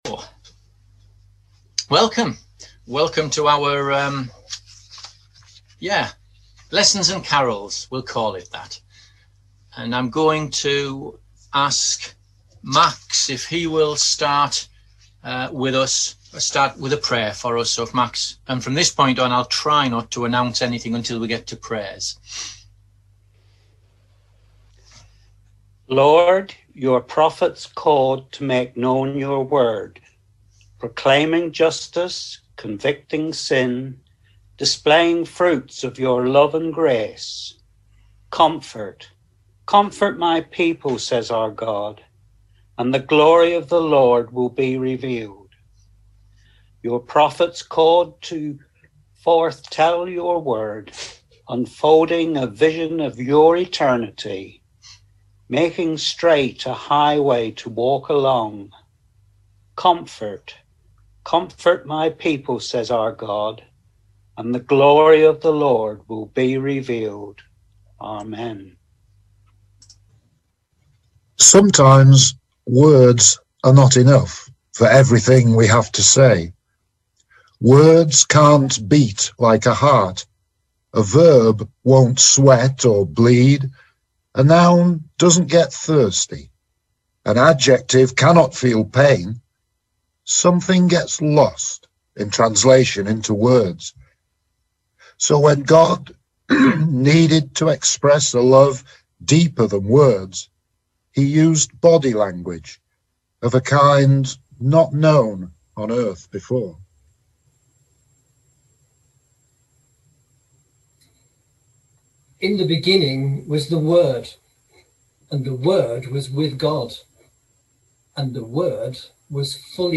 Carol Service/ What has Santa Claus got to do with Jesus? 20 Dec 2020